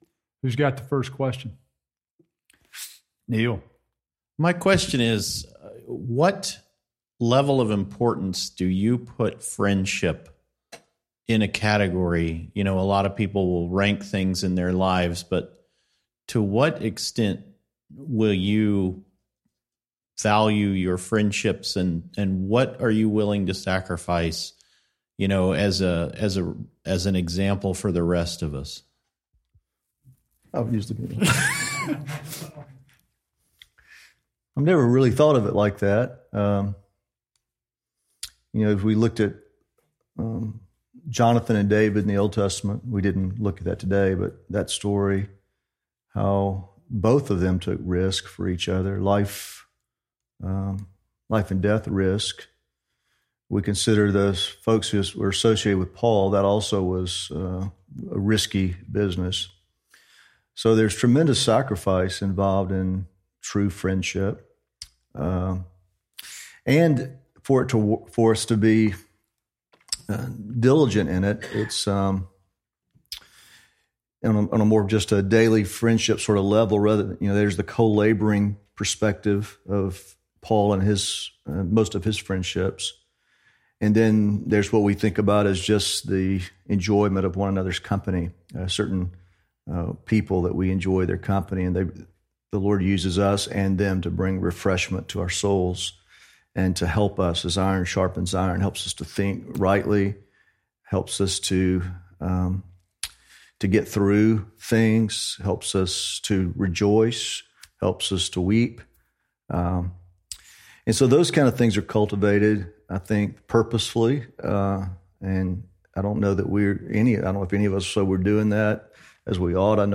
Session 5 Q&A - Panel